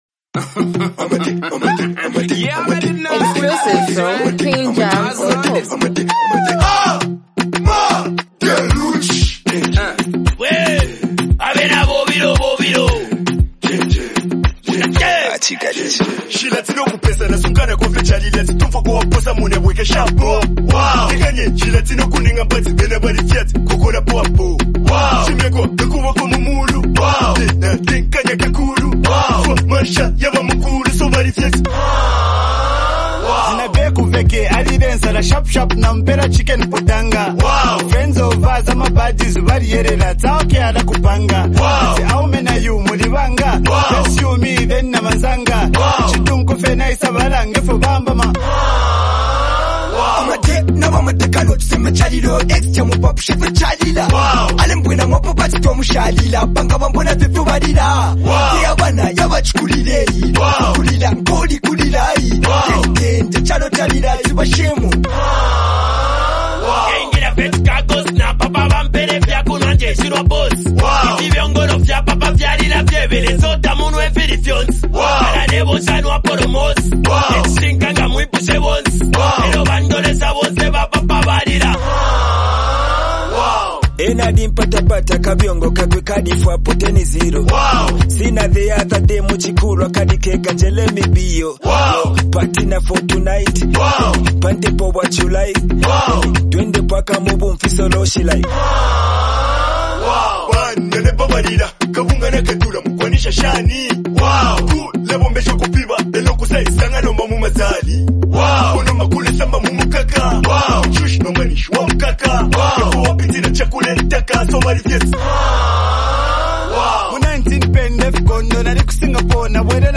an energetic and celebratory song
Backed by a bouncy, upbeat instrumental